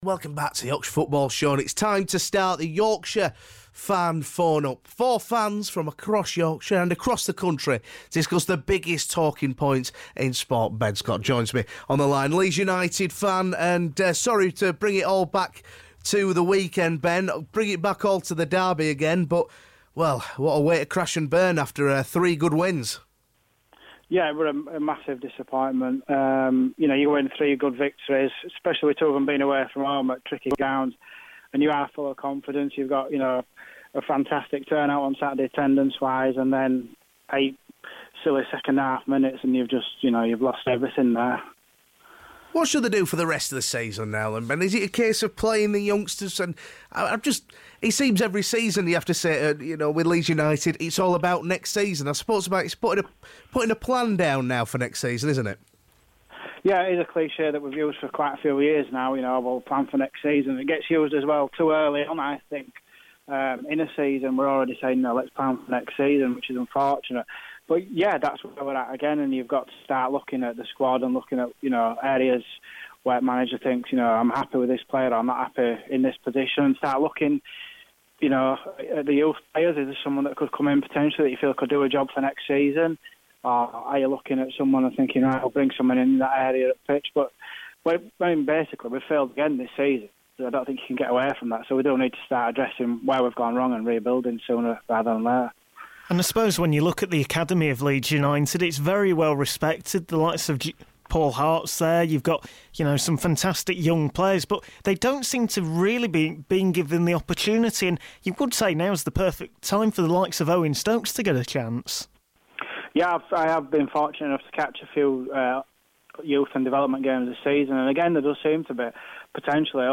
PODCAST: The Yorkshire Football Phone-up - 22nd March
joined by football fans and journalists